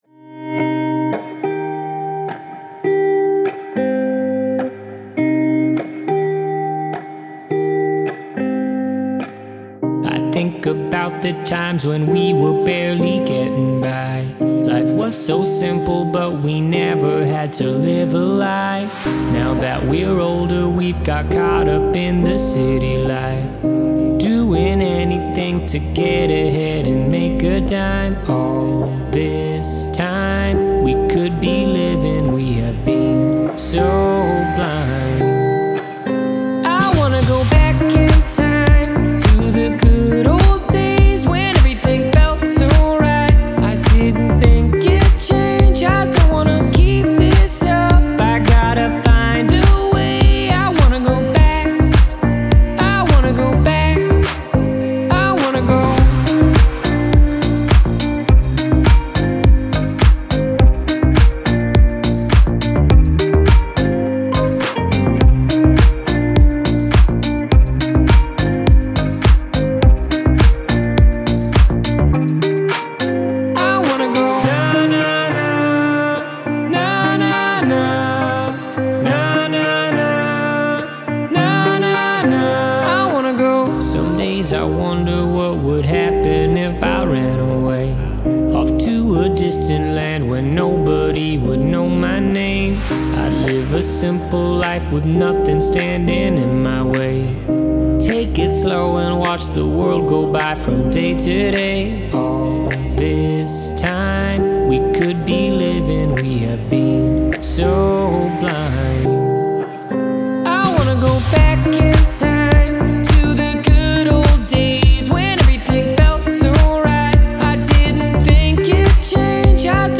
это энергичная электронная композиция в жанре поп и EDM